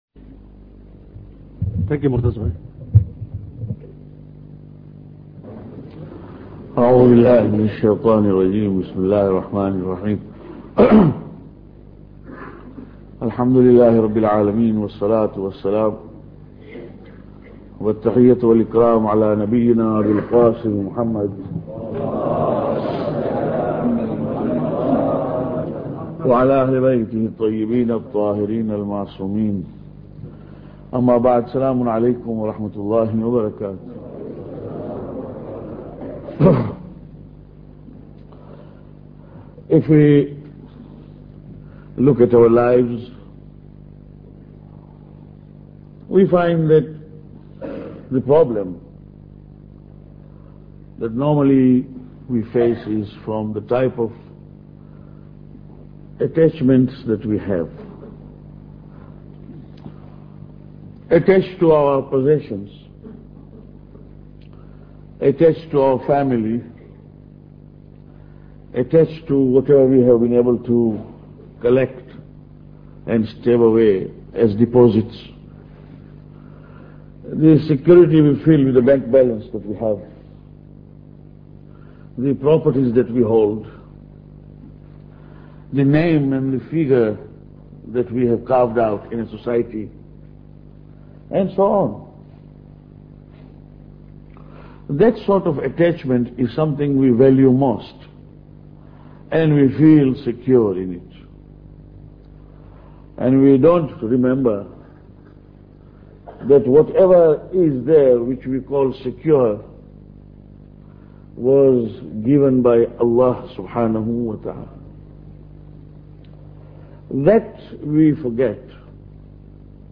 Lecture 4